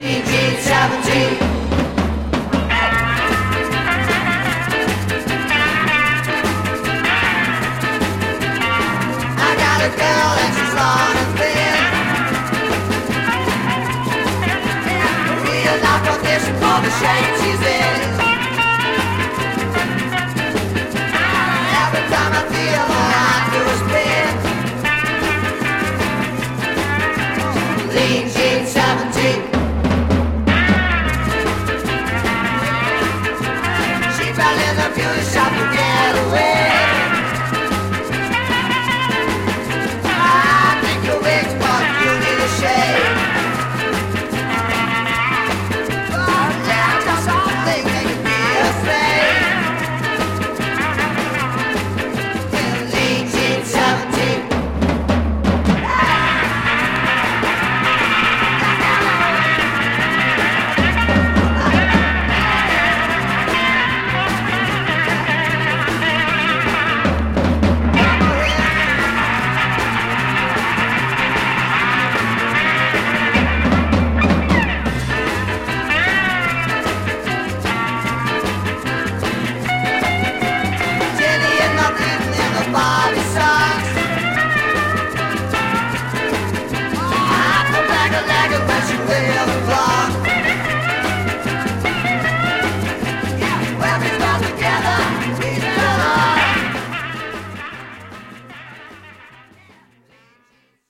Classic German garage beat fuzzy single